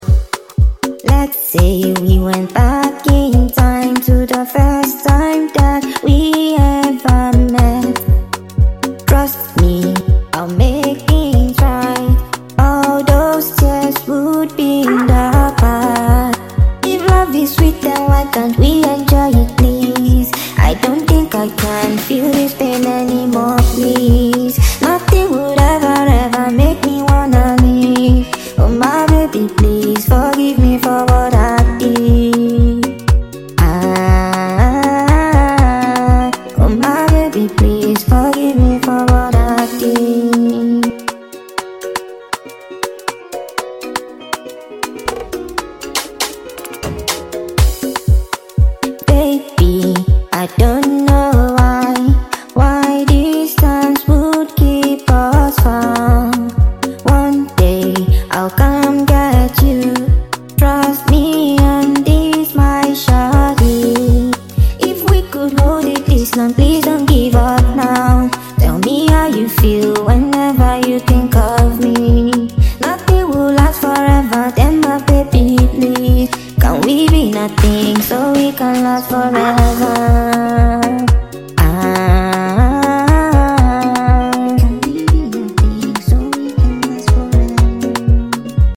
heartfelt and emotional record
feel-good energy and irresistible rhythm